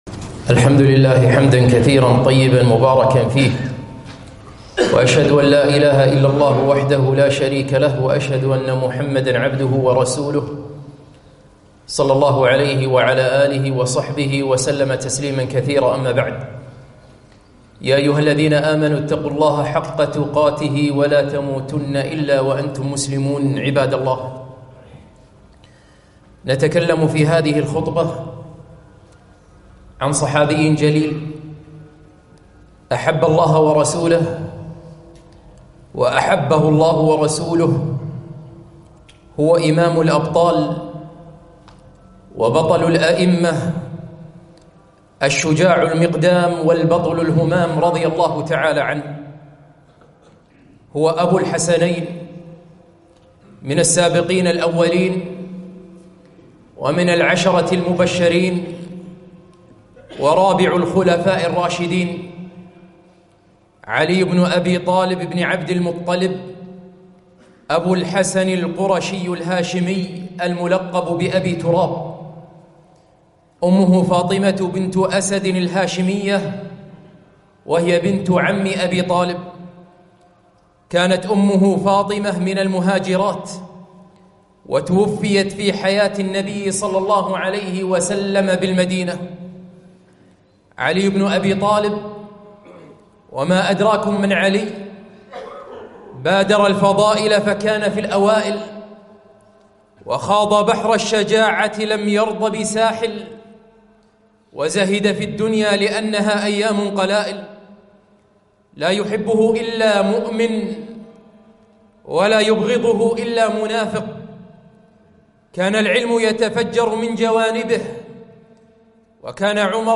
خطبة - علي بن أبي طالب رضي الله عنه - دروس الكويت